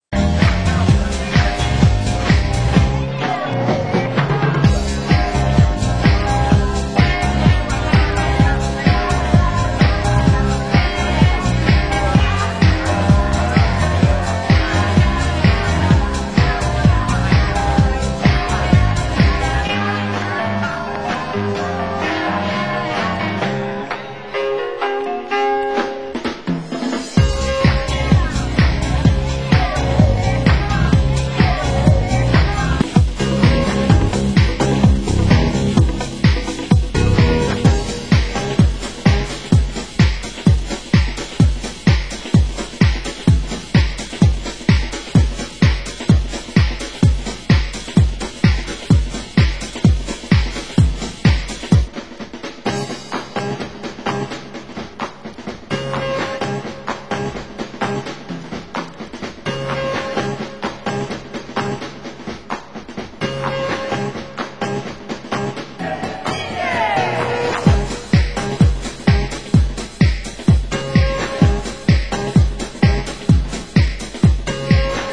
Format: Vinyl 12 Inch
Genre: Funky House